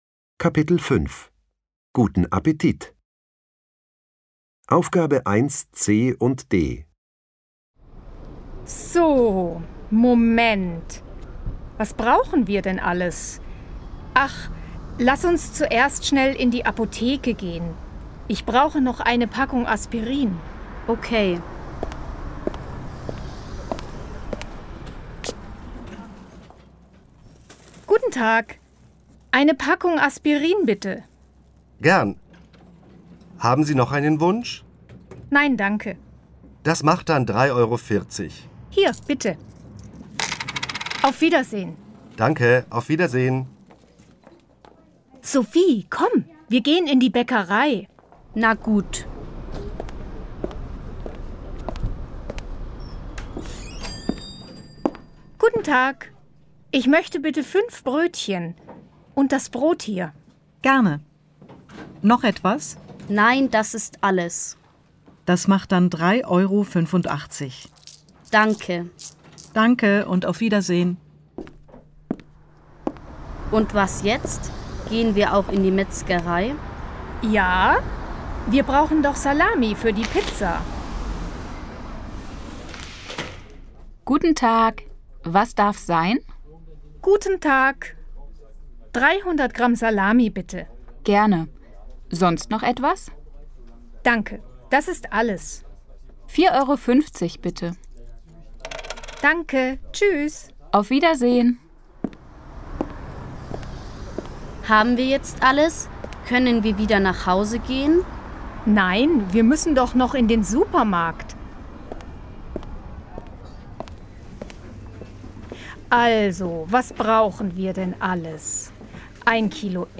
Hallás utáni gyakorlat: